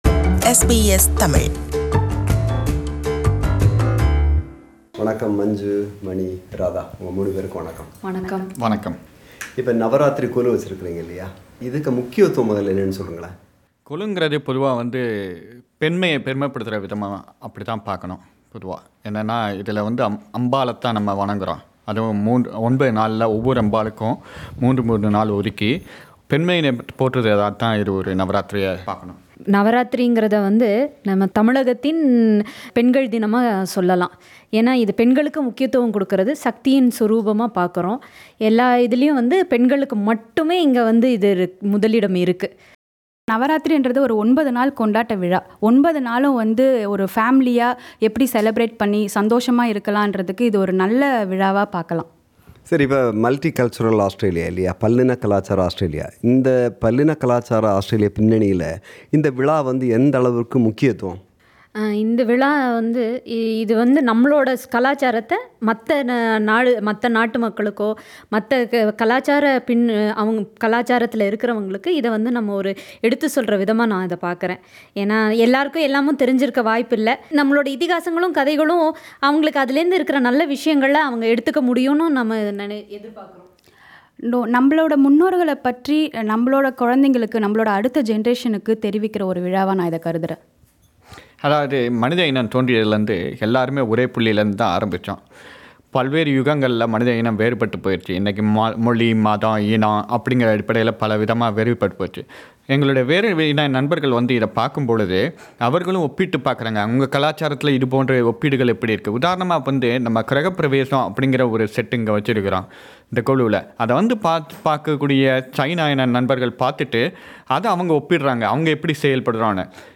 SBS Tamil visited their place this time and requeted them to explain the significants of Golu.